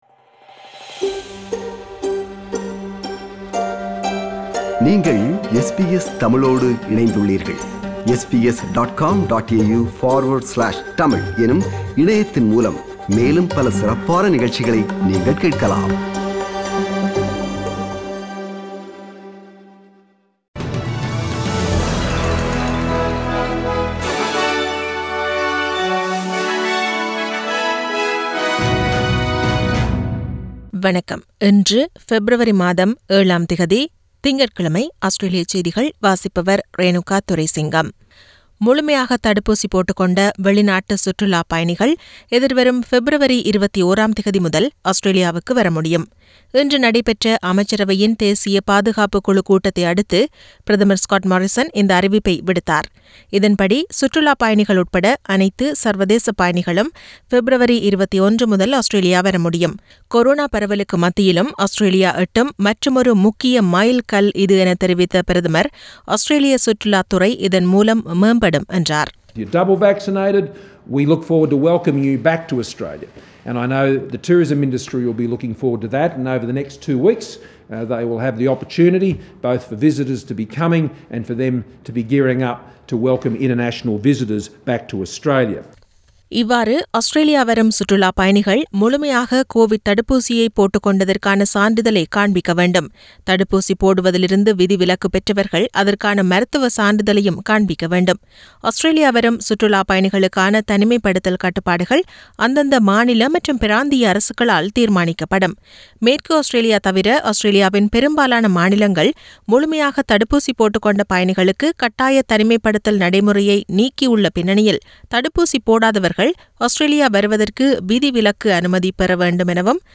Australian news bulletin for Monday 7 Feb 2022.